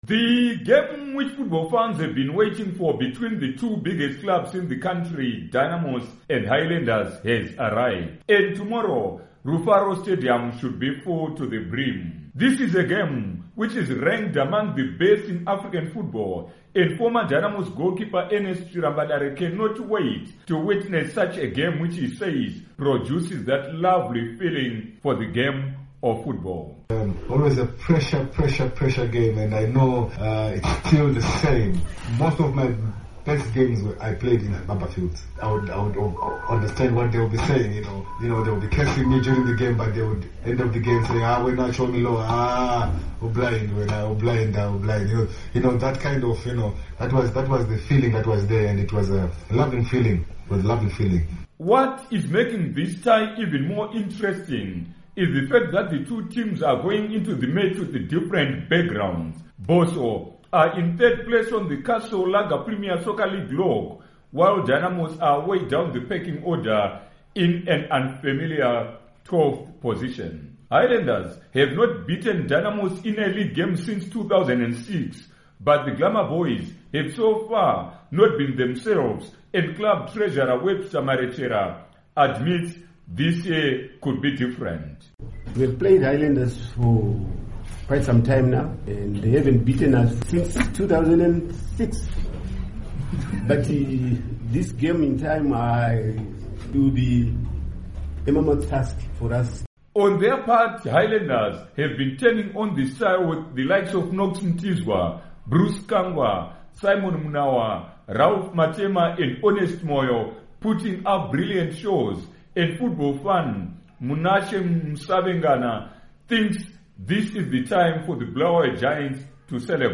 Report on Sports Derby